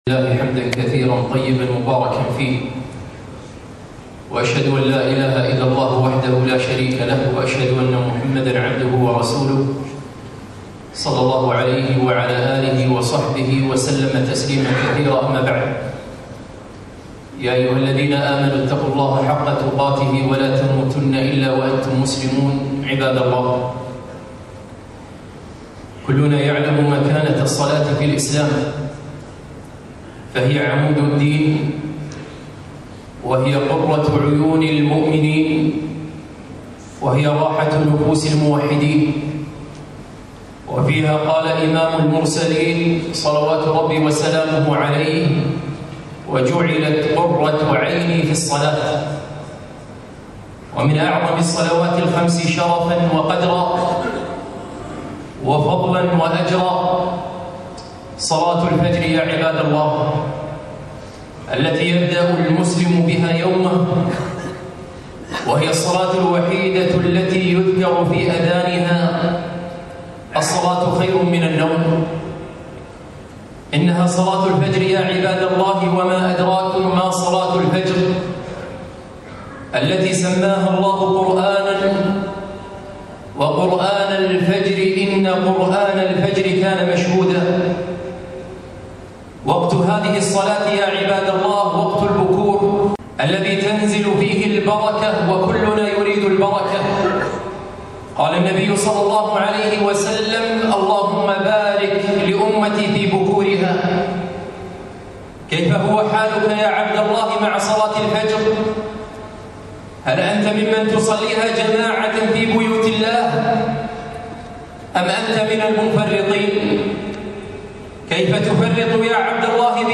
خطبة - صلاة الفجر